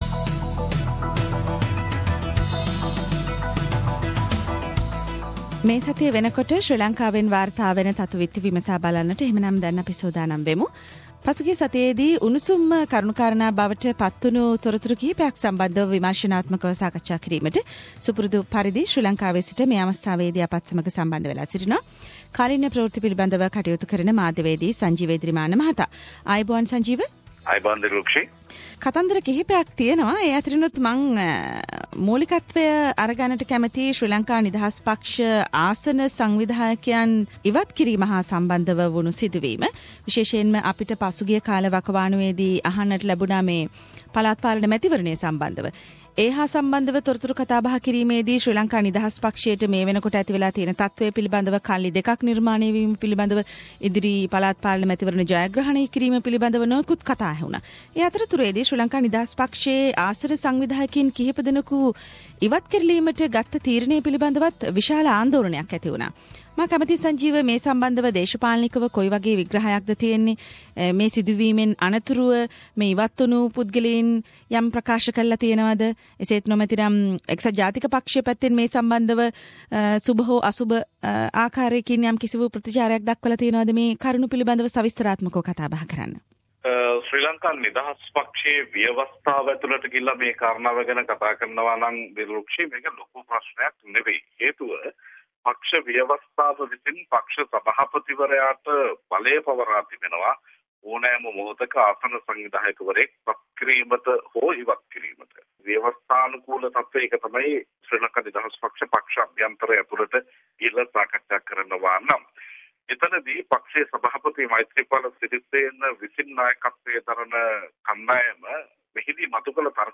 Weekly Sri Lankan News Wrap